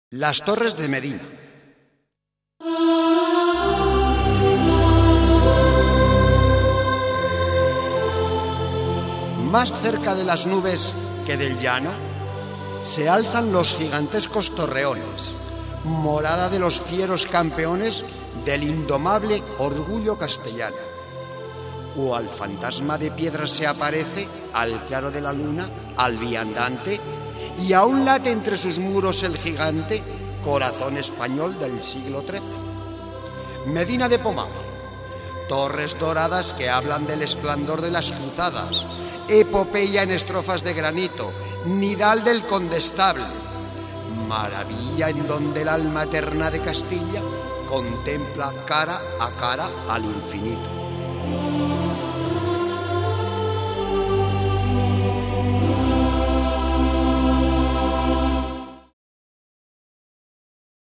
Himno a la residencia del Rosario.
HIMNO VERSIÓN CORAL